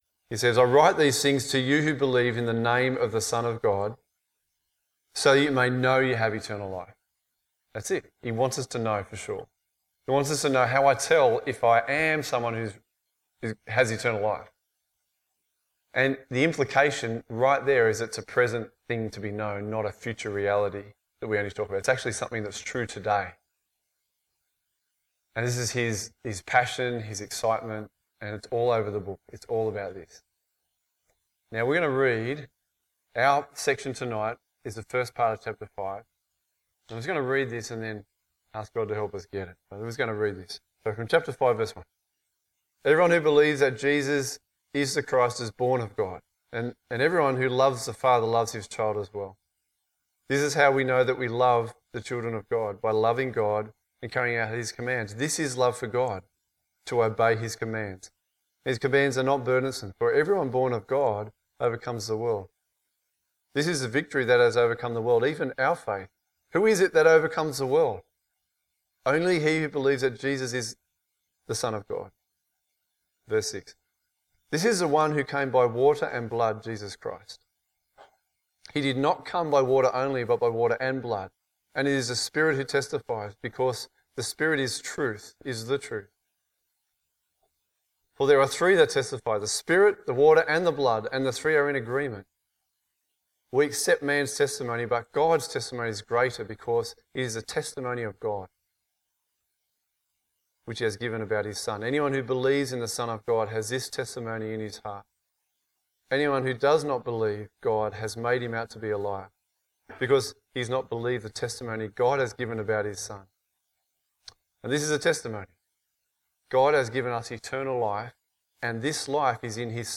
A message from the series "Proof Of Life (1 John)."